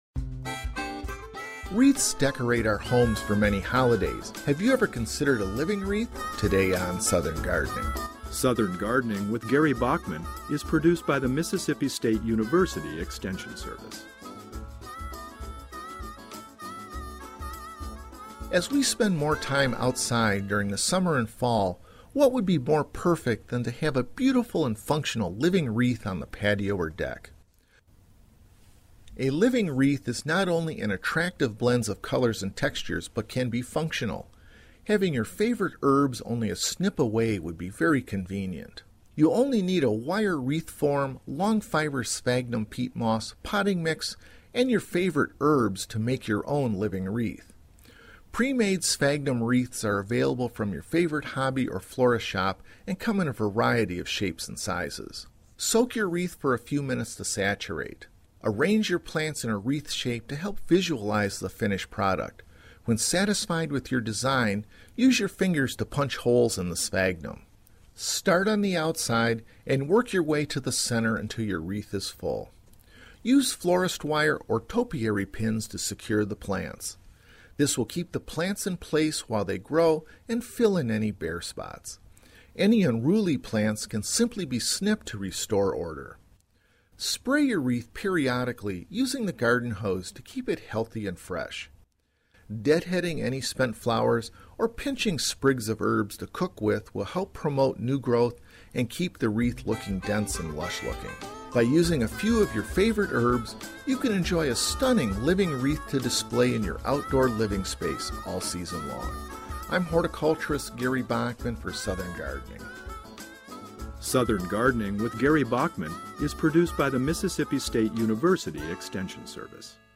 Ornamental Horticulture Specialist